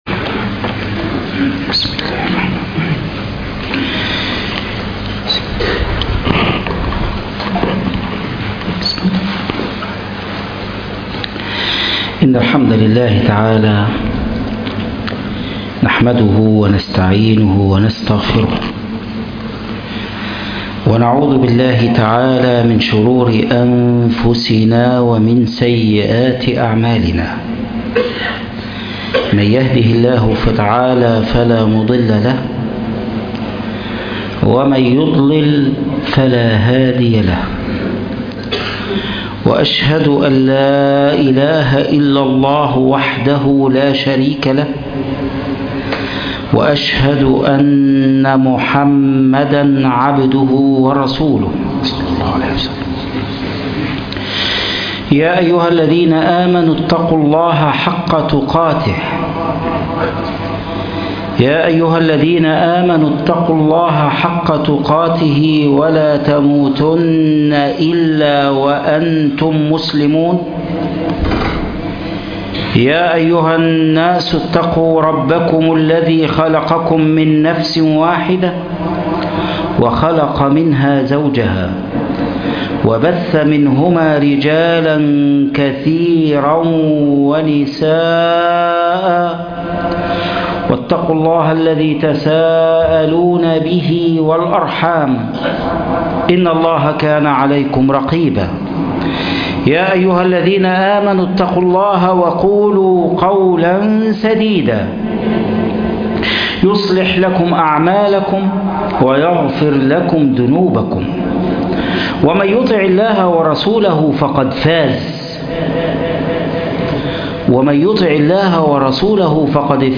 بلال بن رباح - خطبة الجعة